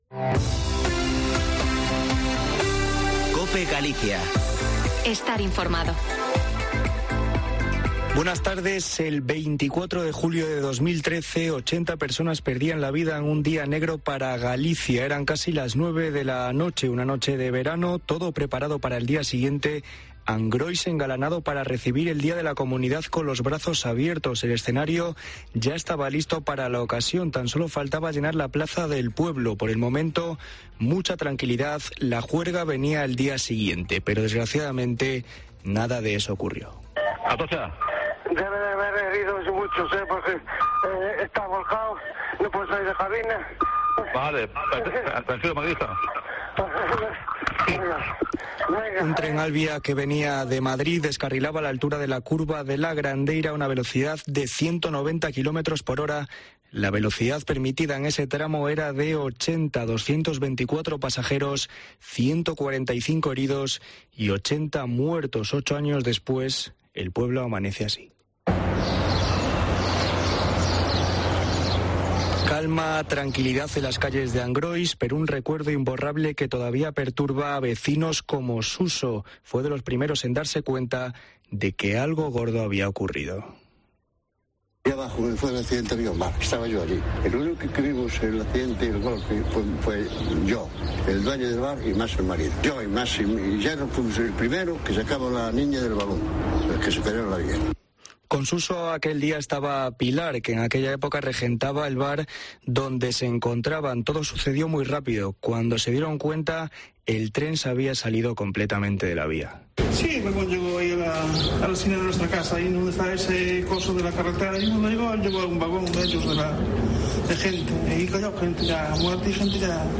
Informativo Mediodía en Cope Galicia 22/07/2021. De 14.48 a 14.58h